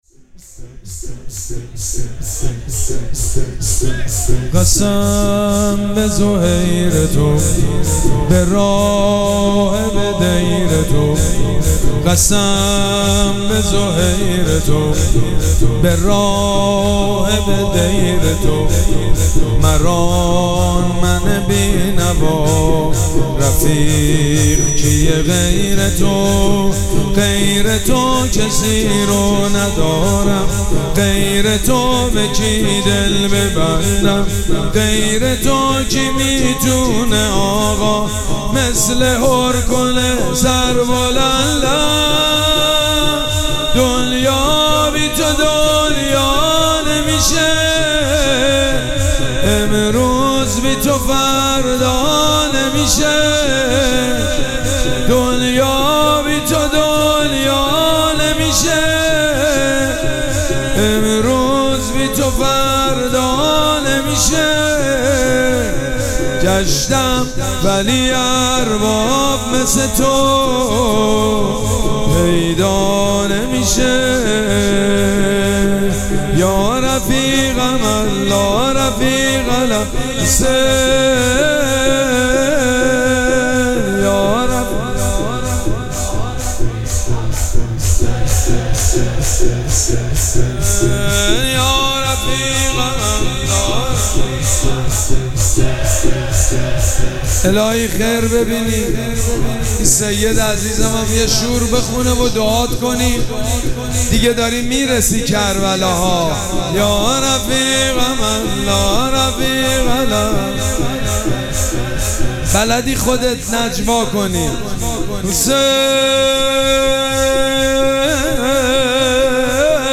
شب چهارم مراسم عزاداری اربعین حسینی ۱۴۴۷
شور
مداح
حاج سید مجید بنی فاطمه